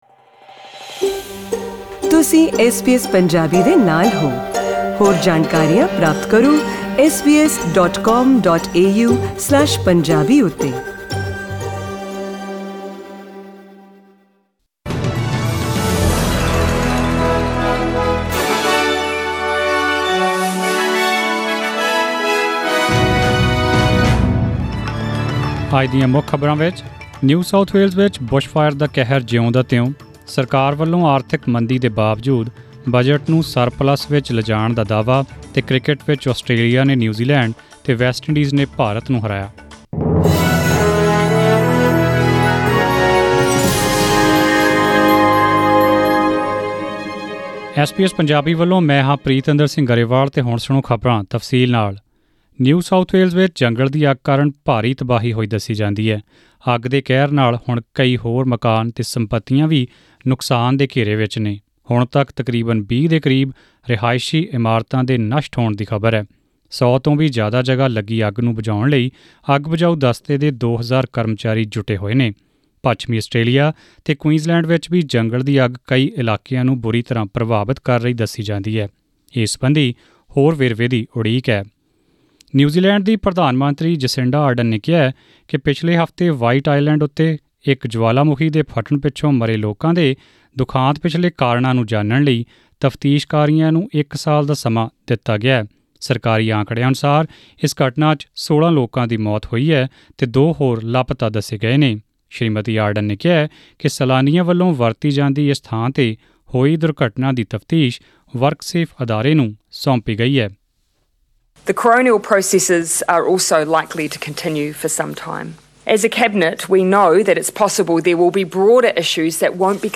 SBS Punjabi News: December 16, 2019